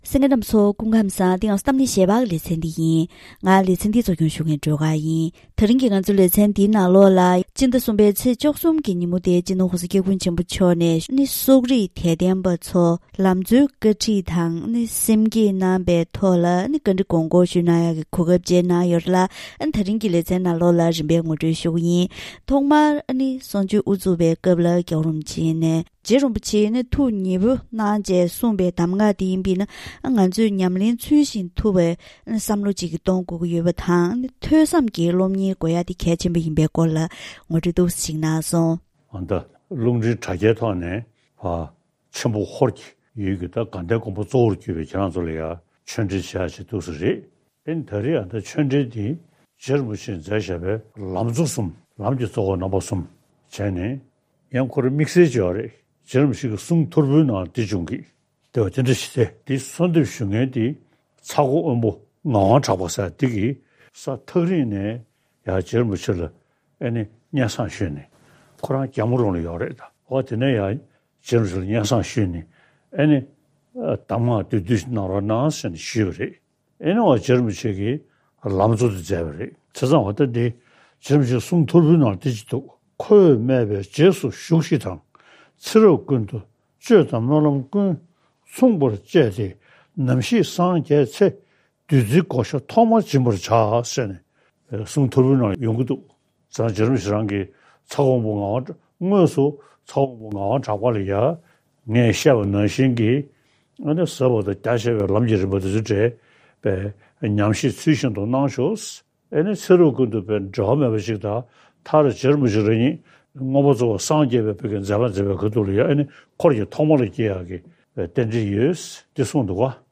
ཚེས་༡༣ཉིན་བཞུགས་སྒར་ཕོ་བྲང་ནས་གསུང་ཆོས་གནང་སྐབས།